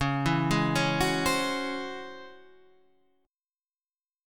DbmM11 chord